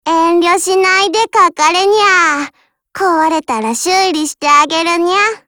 Cv-31201_warcry.mp3